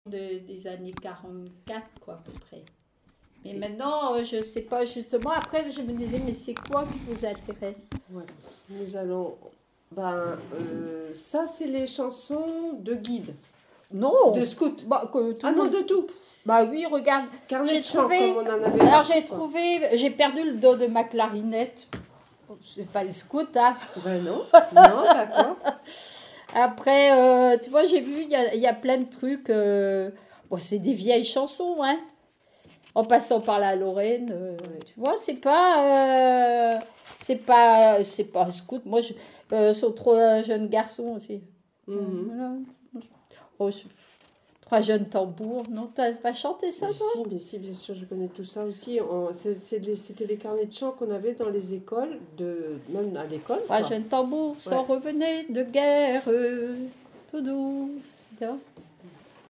Enquête Douarnenez en chansons
Catégorie Témoignage